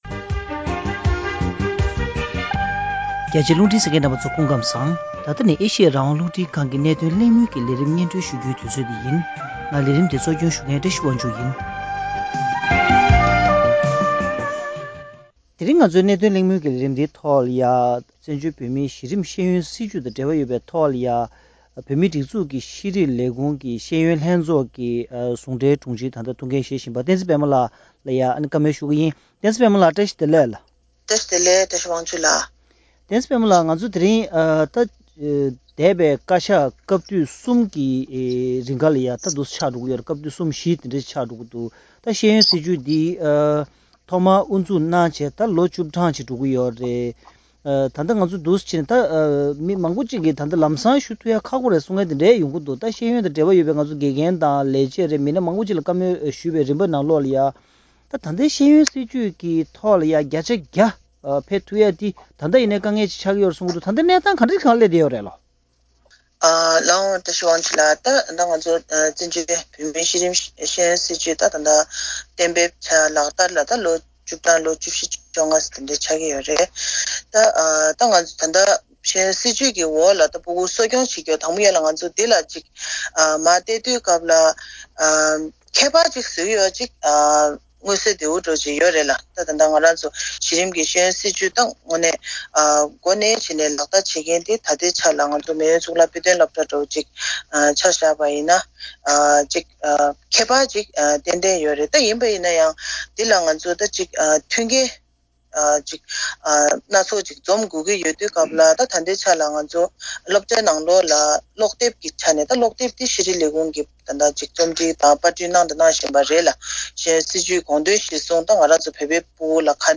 བཅར་འདྲི་ཞུས་པ།